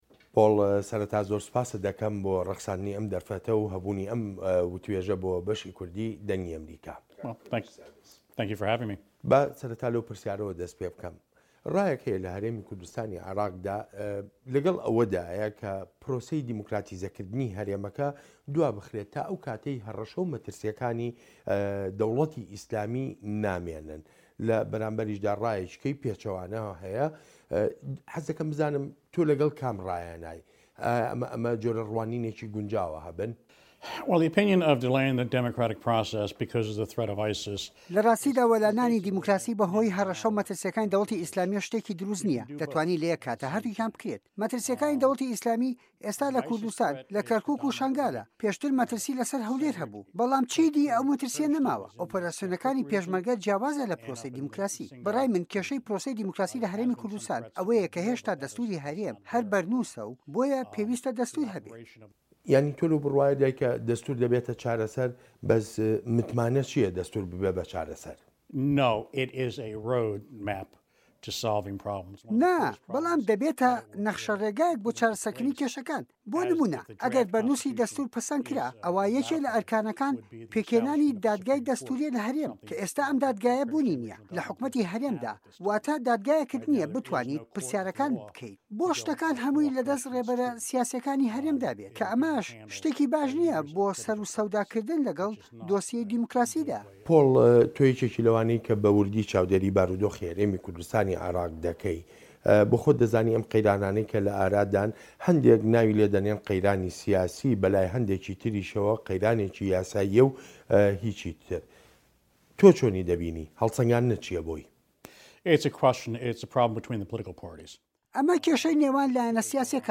دیمانە